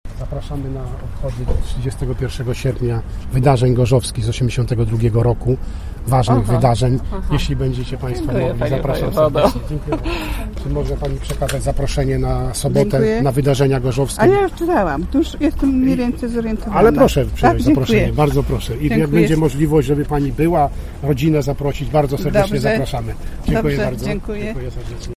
Odwiedzający ryneczek przy Jerzego zaproszenia chętnie przyjmowali.